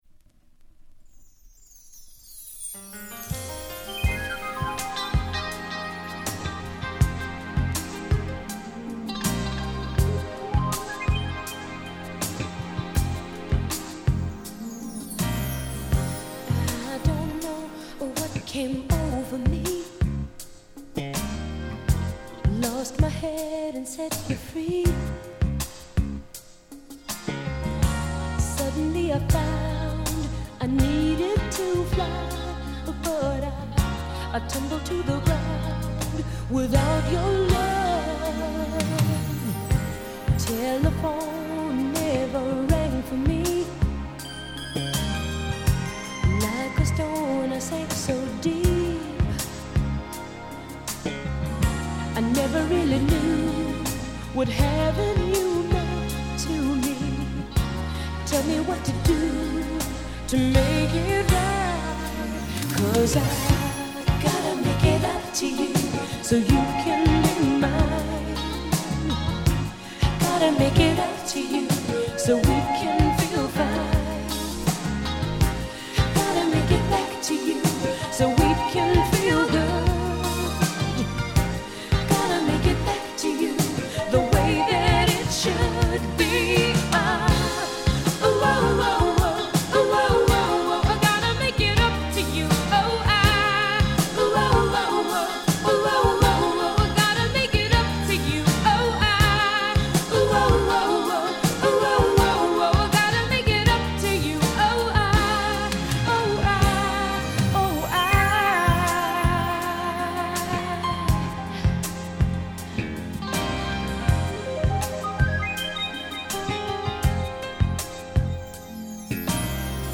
ダンサブル DISCO
Nice Modern Soul/Disco!!